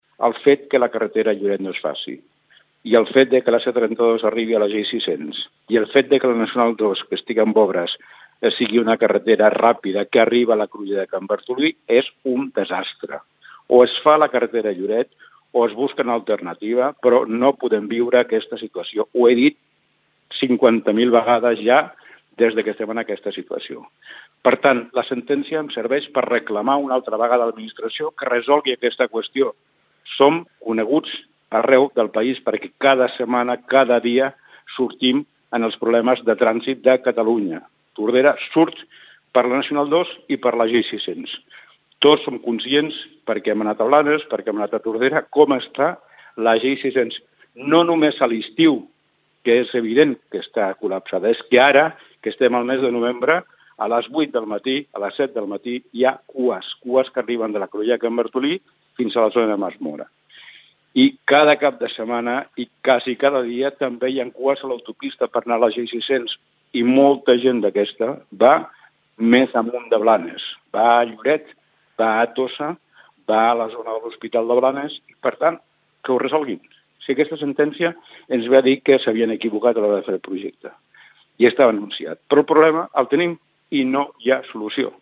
Joan Carles Garcia explica que han demanat una reunió amb la generalitat per abordar la situació i on es reclamarà una solució o que es busqui un Pla B.